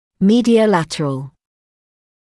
[ˌmiːdɪəu’lætərəl][ˌмиːдиоу’лэтэрэл]медиолатеральный